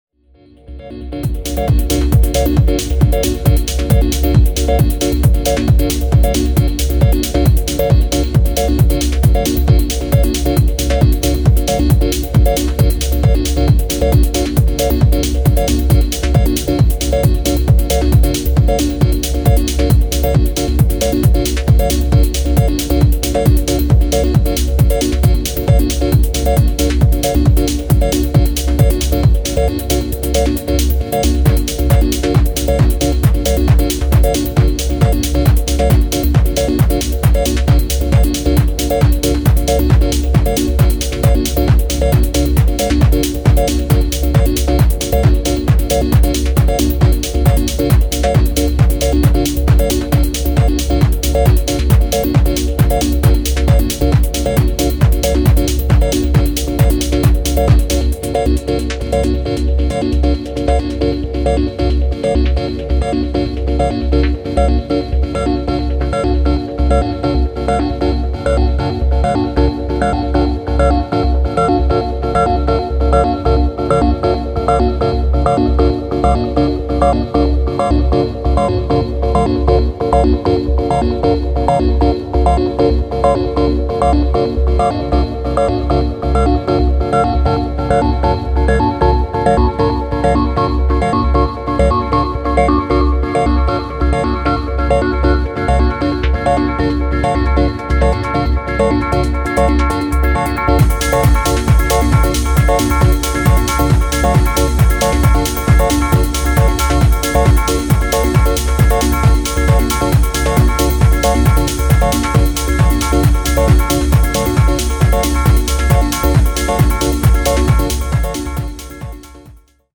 FUNCTIONAL TECHNO CRAFTED TO ELEVATE